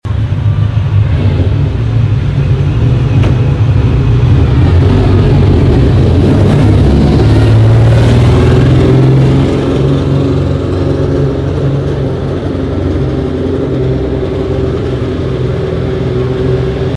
1970 Ferrari 512 S Pininfarina sound effects free download